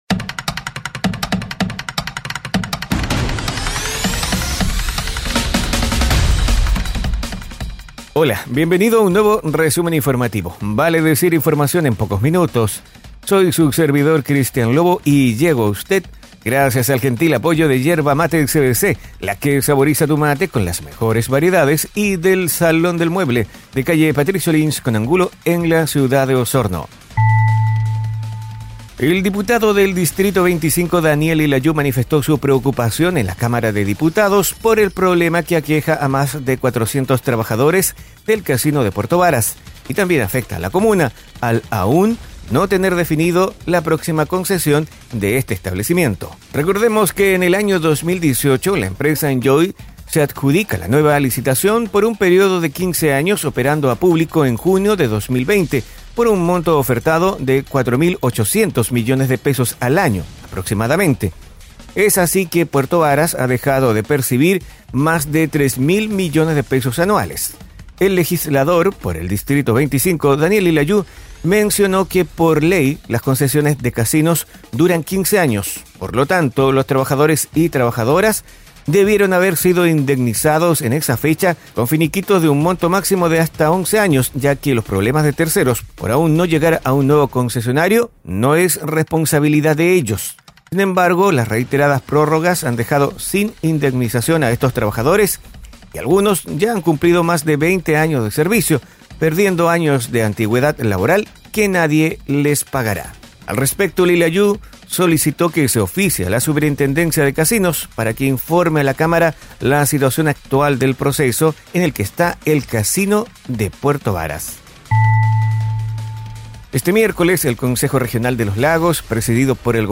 Resumen informativo es un audio podcast con una decena informaciones en pocos minutos, enfocadas en la Región de Los Lagos